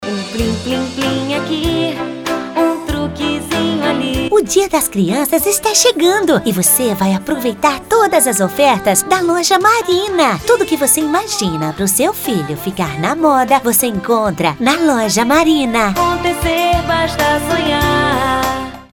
Voz caricata - infantil: